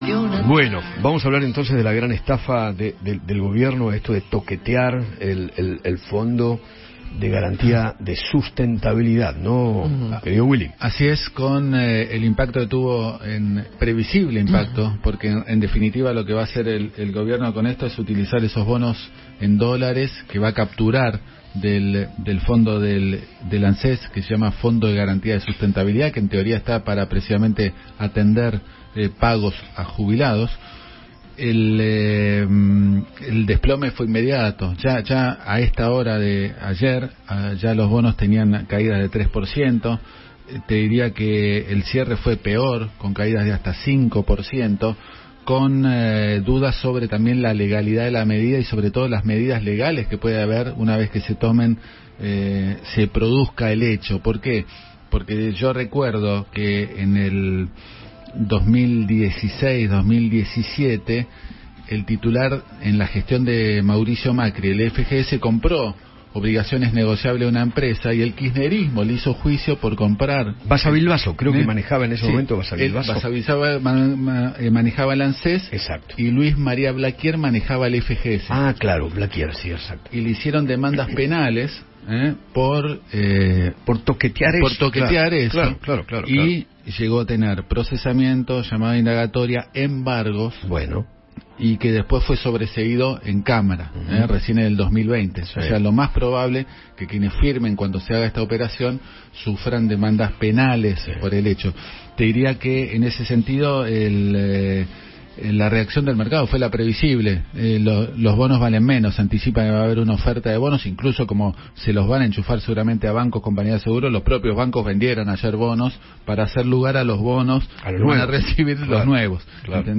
Diego Bossio, ex Director Ejecutivo de ANSES, habló con Eduardo Feinmann tras la decisión del Gobierno de tocar el Fondo de Garantía de Sustentabilidad de ANSES.